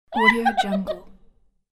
دانلود افکت صدای خنده‌ی خنده دار دختر
Sample rate 16-Bit Stereo, 44.1 kHz